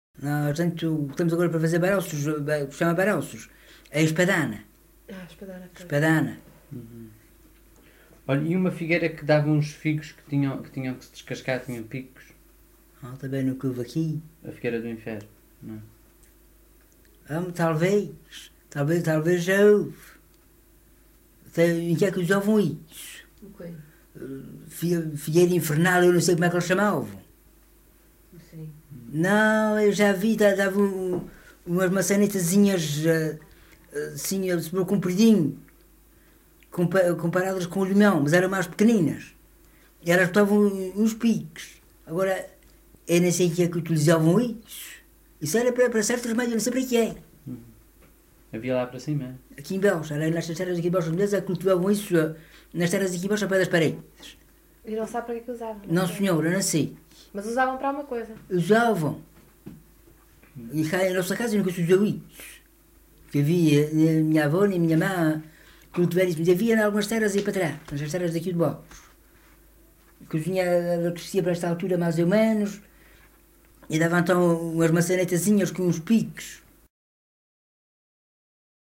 LocalidadeVila do Corvo (Corvo, Horta)